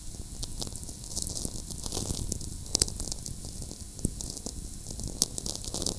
Cumiana, NW Italy